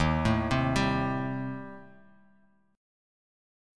Eb6b5 Chord
Listen to Eb6b5 strummed